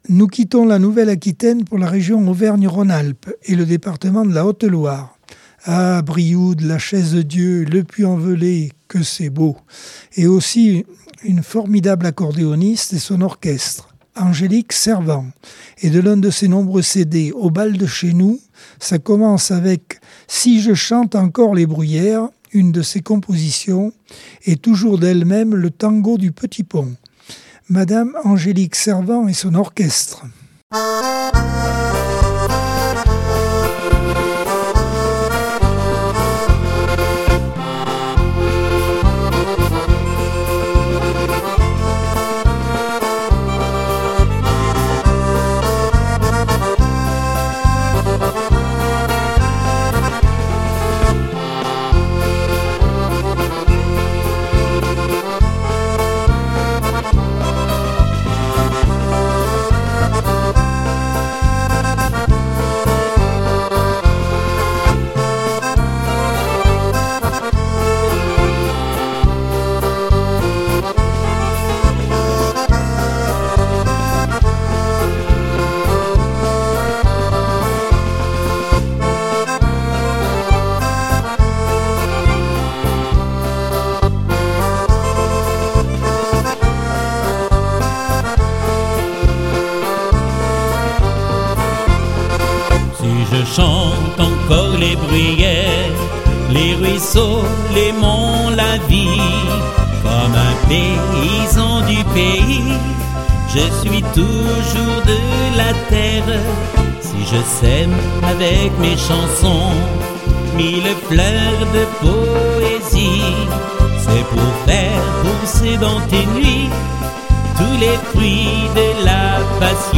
Accordeon 2024 sem 50 bloc 3 - Radio ACX